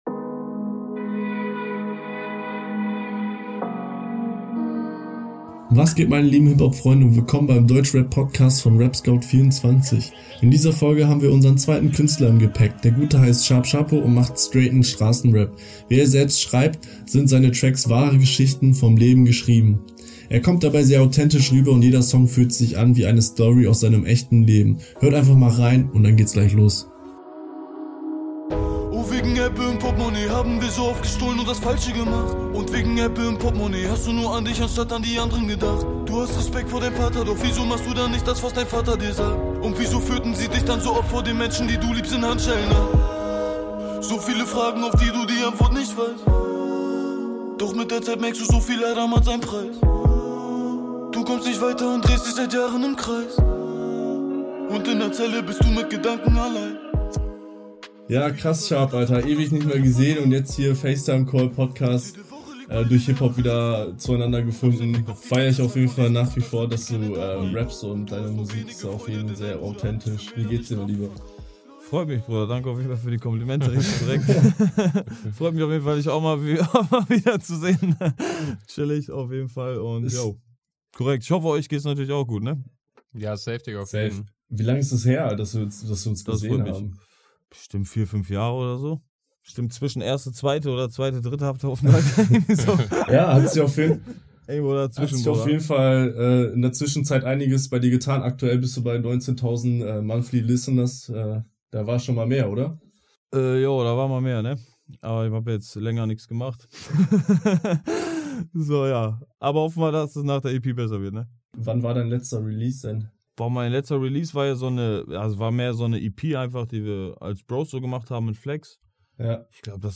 #13 Interview